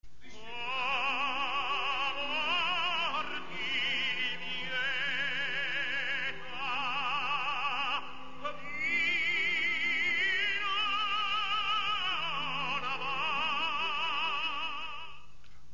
В 1991 году этот испанский тенор исполнил в Венской опере партию Отелло; его вызывали на поклон 101 раз, что заняло полтора часа.